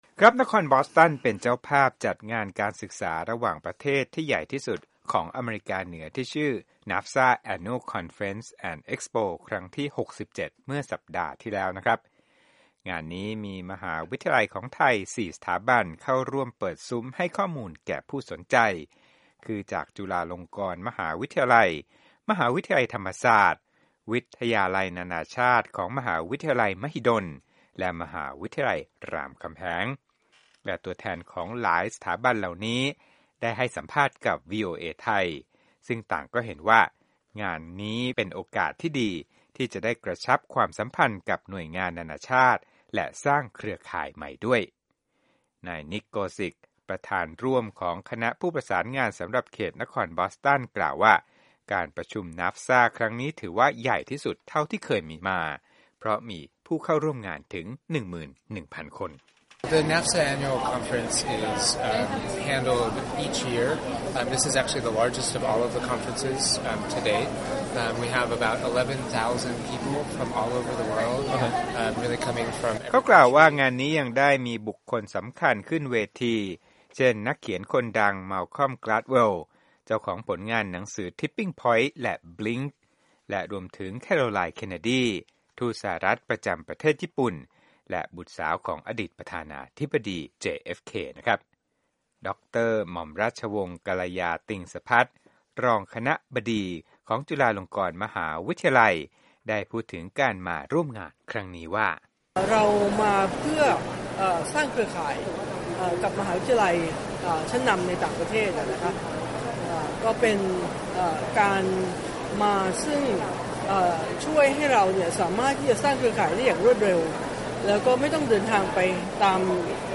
รายงานพิเศษจากที่ประชุม NAFSA